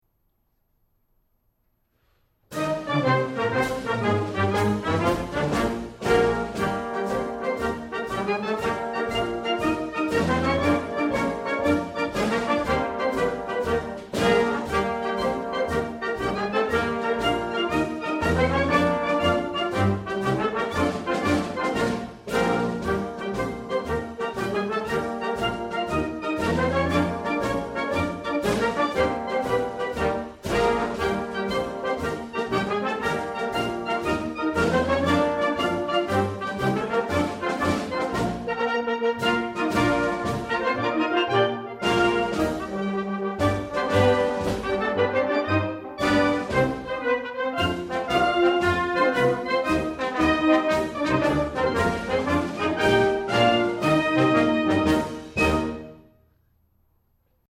These were sight read and recorded on August 7'th 2007.